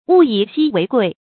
物以希為貴 注音： ㄨˋ ㄧˇ ㄒㄧ ㄨㄟˊ ㄍㄨㄟˋ 讀音讀法： 意思解釋： 事物因稀少而覺得珍貴。